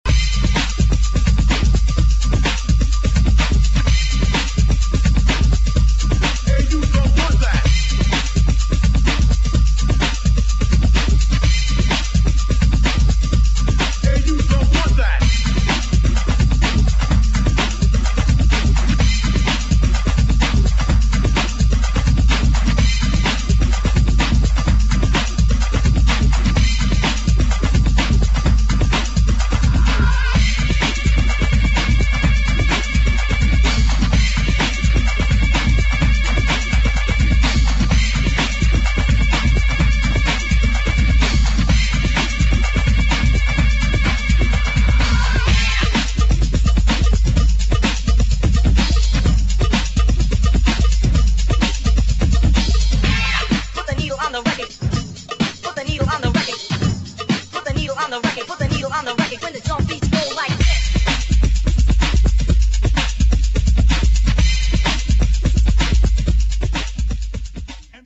[ DUBSTEP / UK GARAGE / BREAKS ]